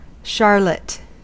Ääntäminen
Ääntäminen US Haettu sana löytyi näillä lähdekielillä: englanti Käännös Substantiivit 1. charlotte {f} Määritelmät Substantiivit A dessert containing sponge , fruit and cream or custard .